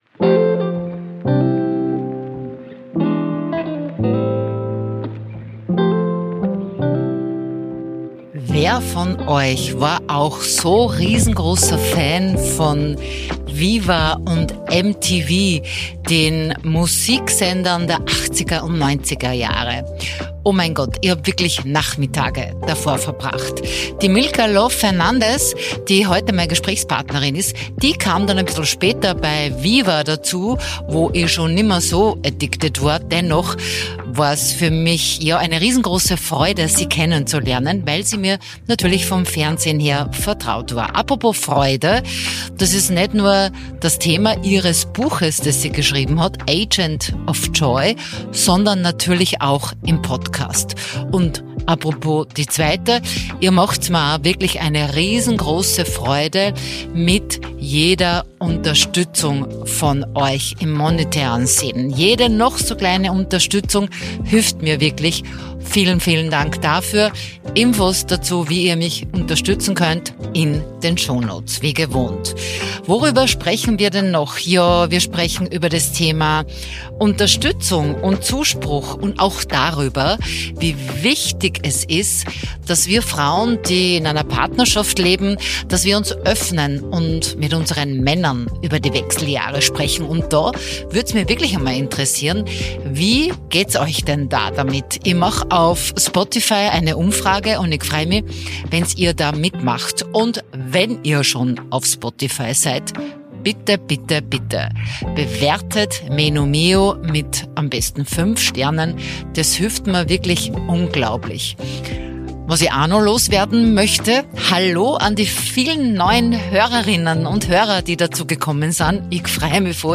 Ein ehrlicher Austausch über den Mut, in den größten Umbrüchen des Lebens die eigene Freude wiederzufinden.